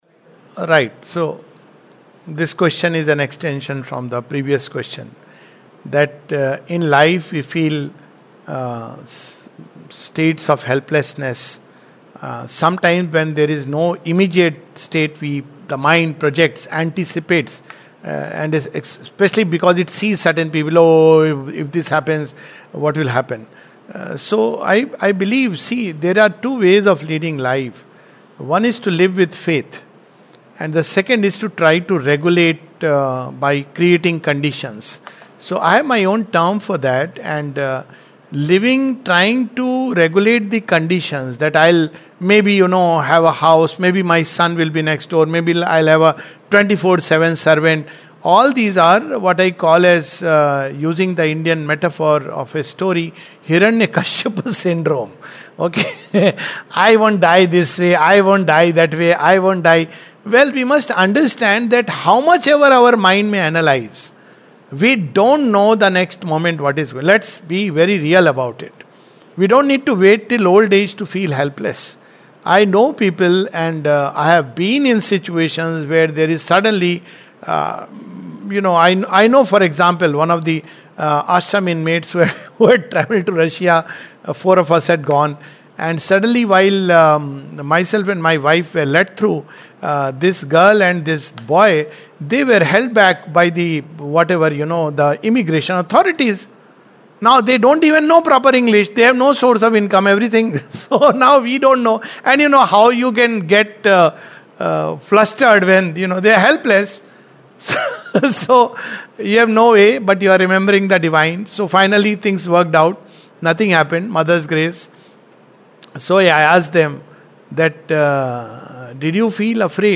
Replying to questions asked by the staff of Auro University, Surat.